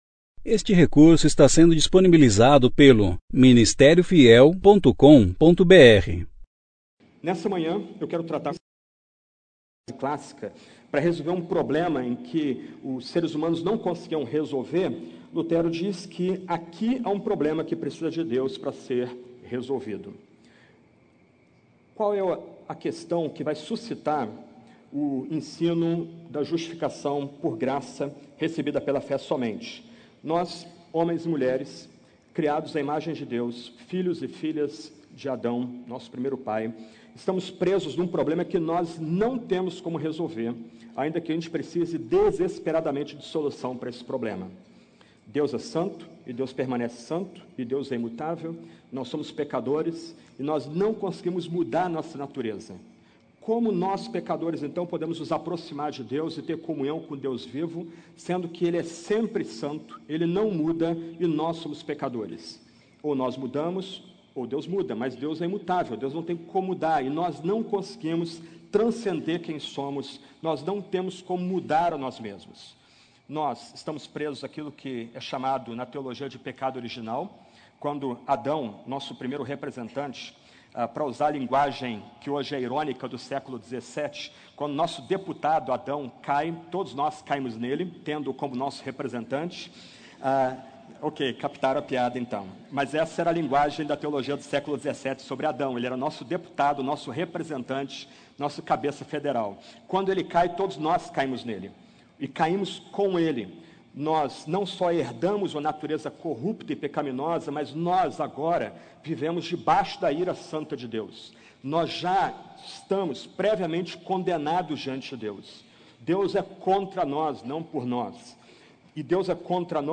Conferência: 33ª Conferência Fiel para Pastores e Líderes – Brasil Tema